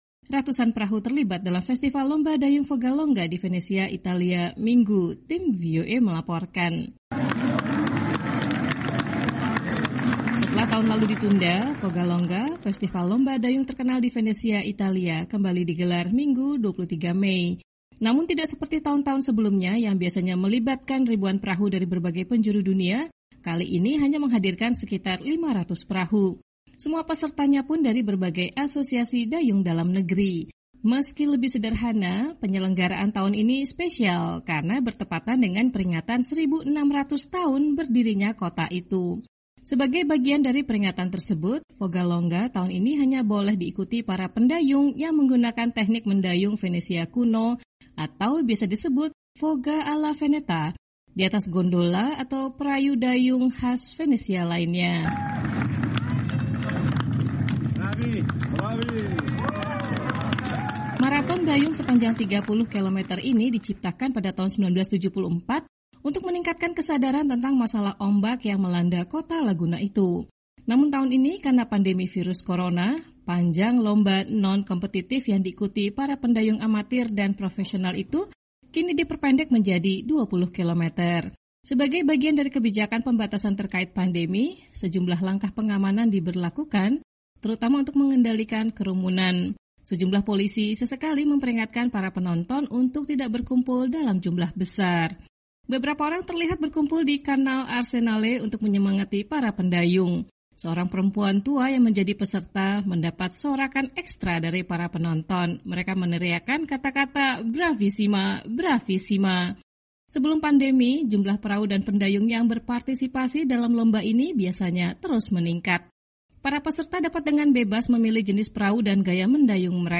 Tim VOA melaporkan.